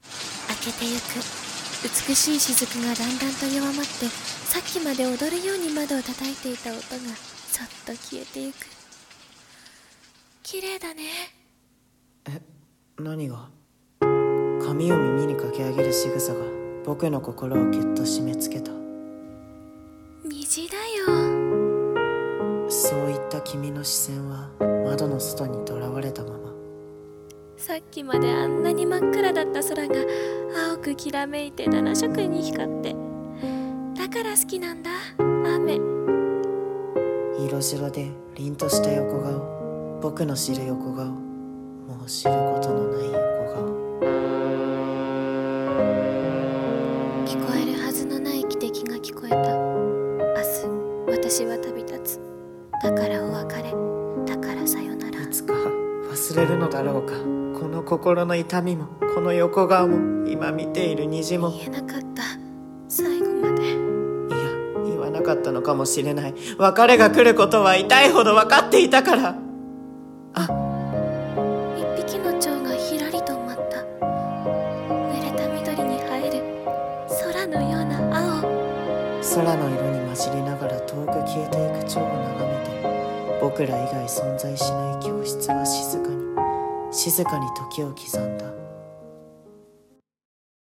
二人声劇】蒼と蝶